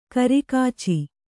♪ kari kāci